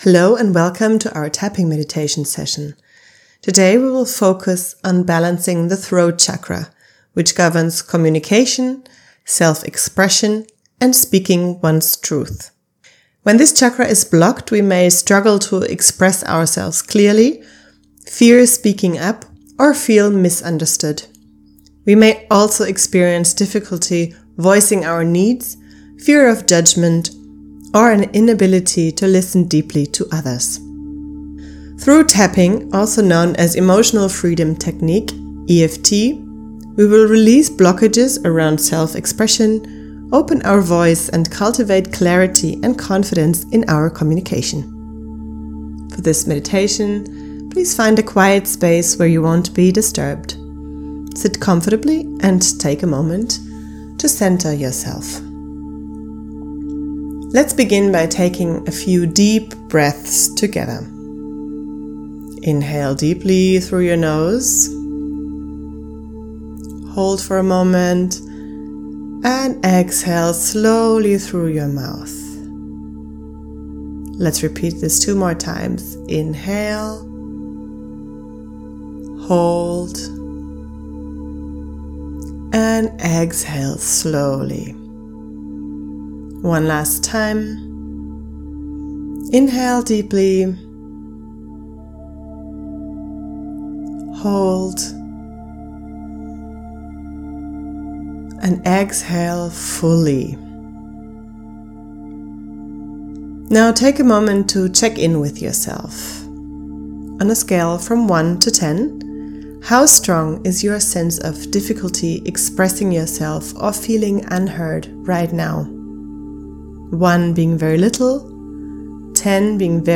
Throat-Tapping-Meditation.mp3